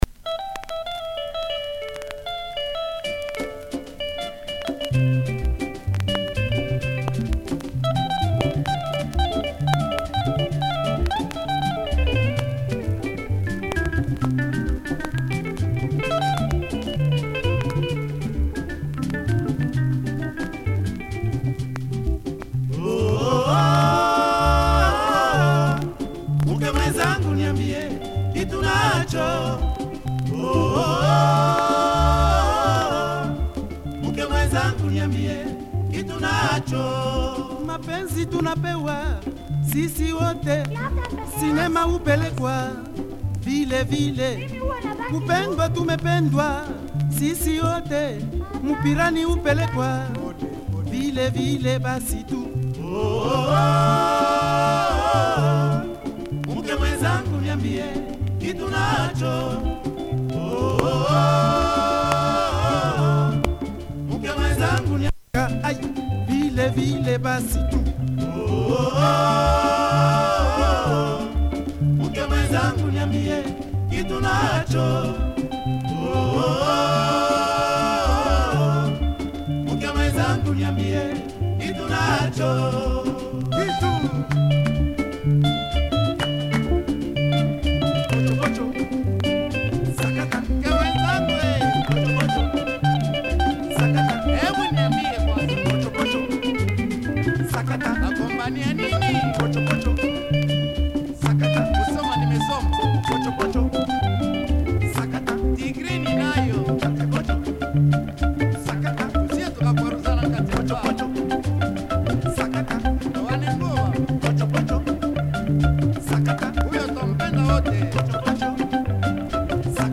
Some sublime guitar playing here
Stock copy but Micro groove so could do with an extra clean.